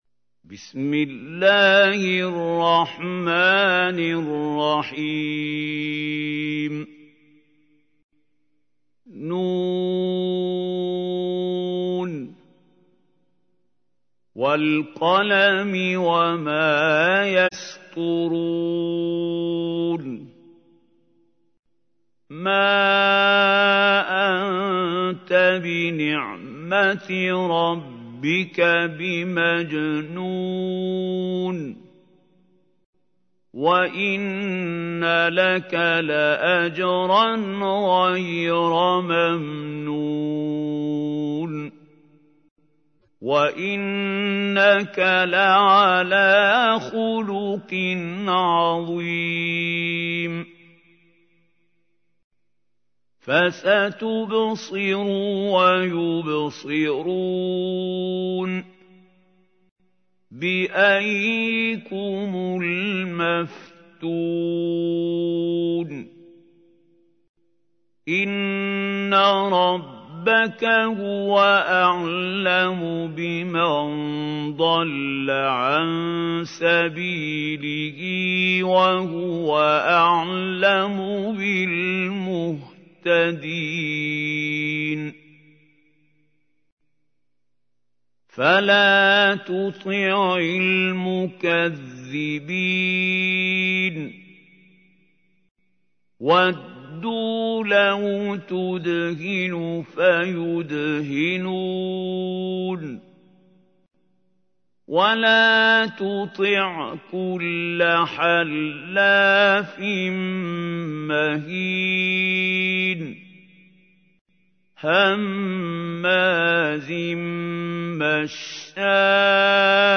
تحميل : 68. سورة القلم / القارئ محمود خليل الحصري / القرآن الكريم / موقع يا حسين